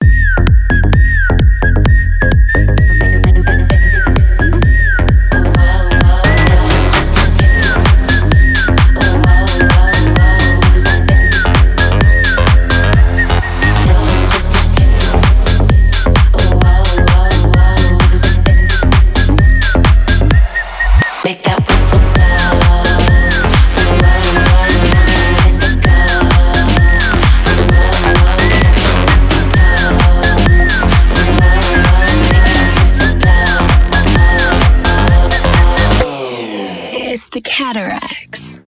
танцевальные
свист